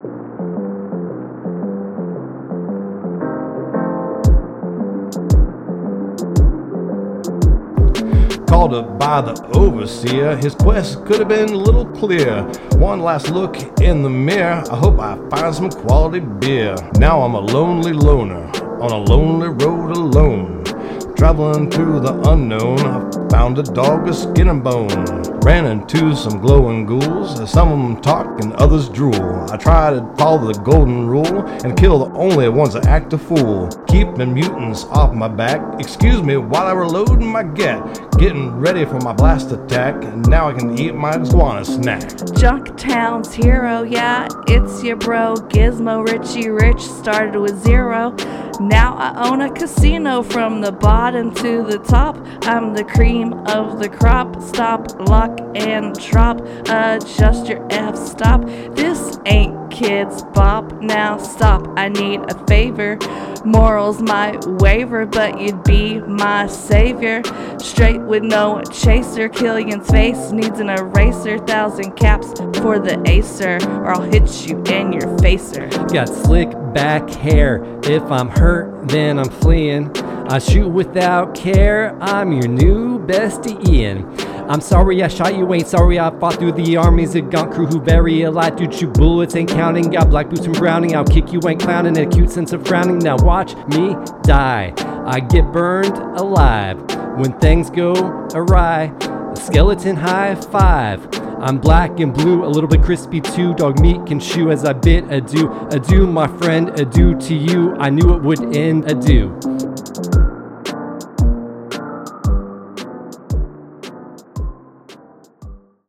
Rap from Episode 70: Fallout – Press any Button
fallout-rap.mp3